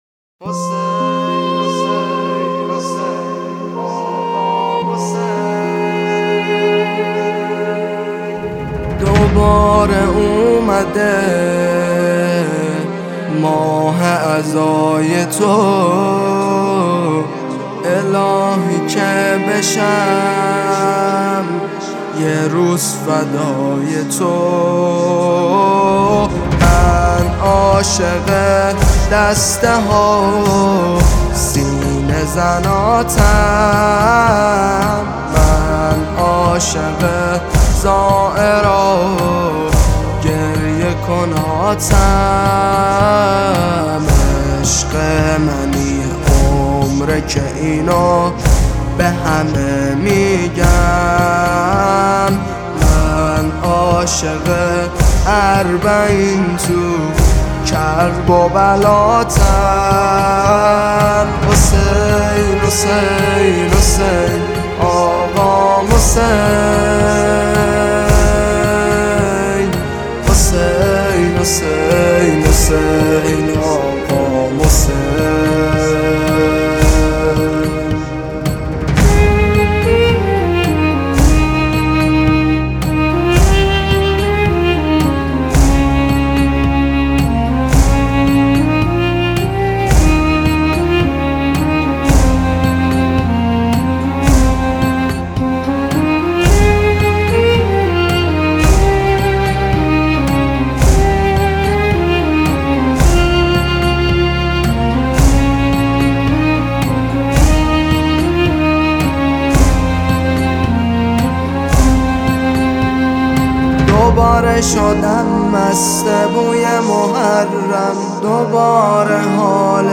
نماهنگ ویژه چاووش محرم